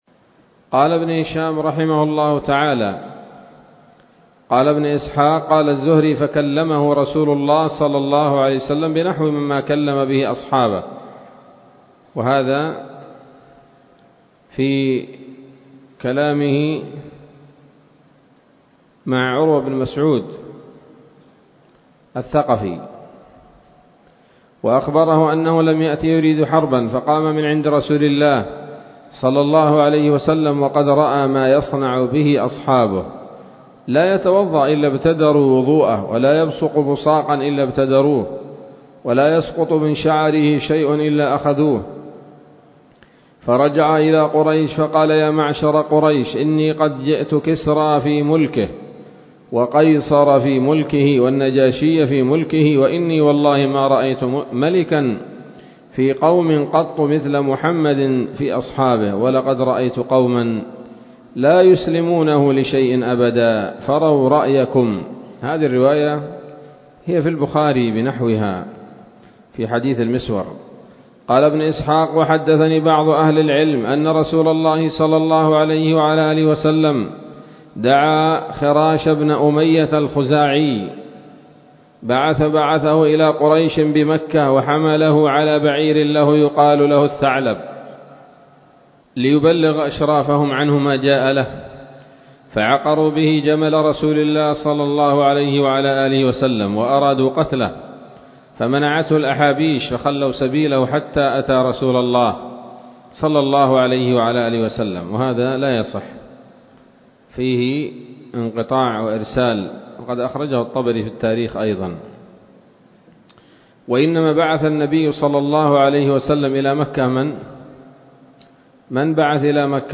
الدرس الحادي والثلاثون بعد المائتين من التعليق على كتاب السيرة النبوية لابن هشام